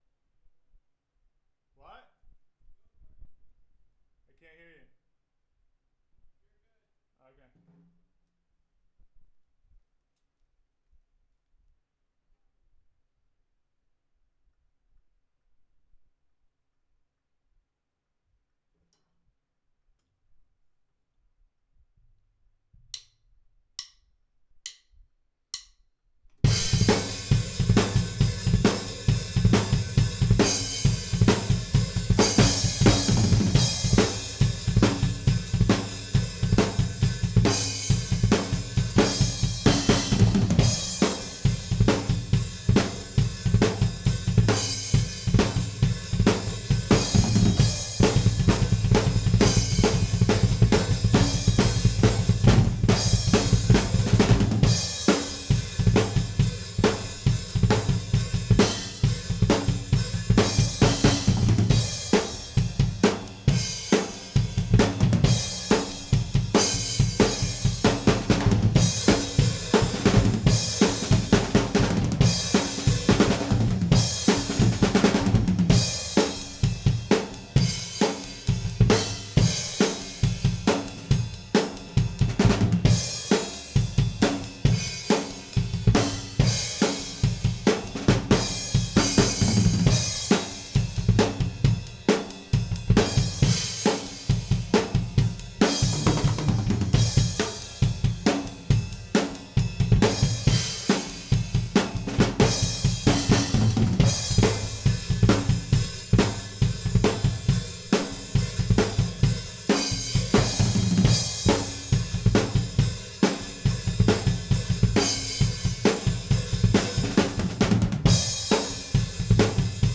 Cosmic Drums Basic.wav